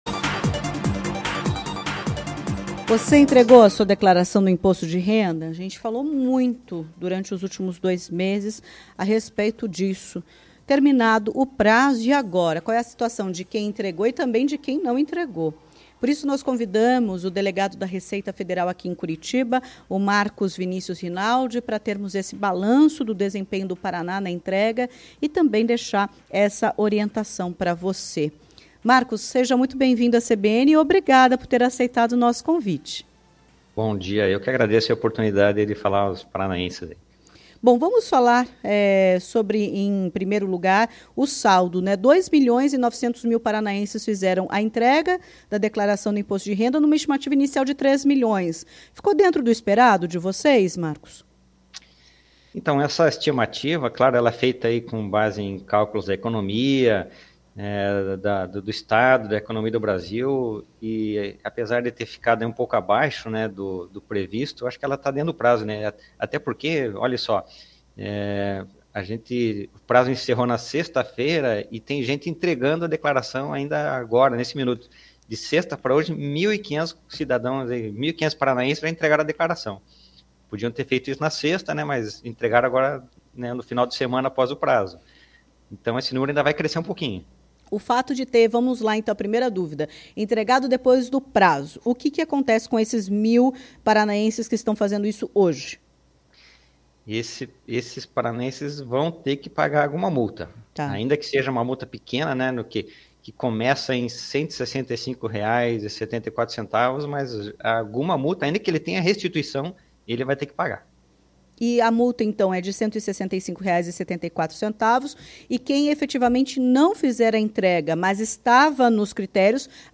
Em entrevista à CBN Curitiba, o delegado da Receita Federal em Curitiba, Marcos Vinícius Rinaldi, explicou sobre a malha fina, multas para quem não entregou a declaração e as restituições.